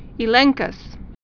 (ē-lĕngkəs)